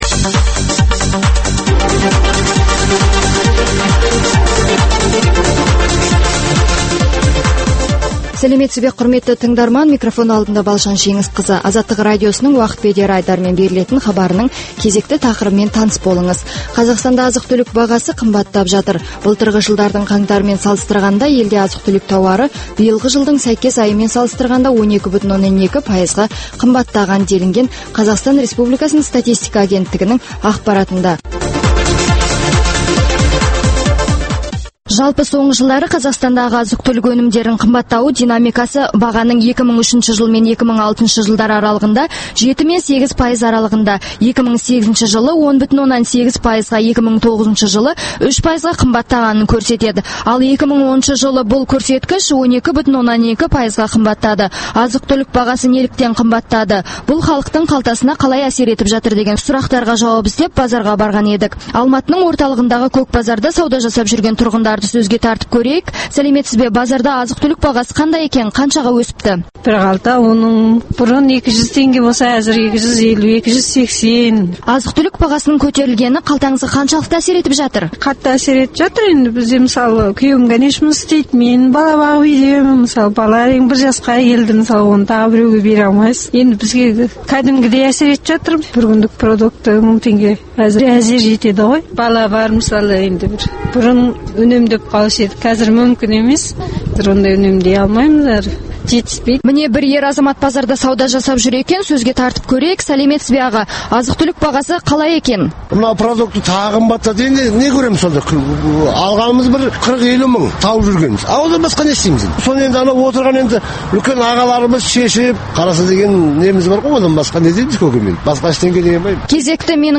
Мұндай сұрақтарға жауап іздеп Алматының орталығындағы көк базарға барған Азаттық тілшілері сауда жасап жүрген тұрғындарды сөзге тартып көрген еді.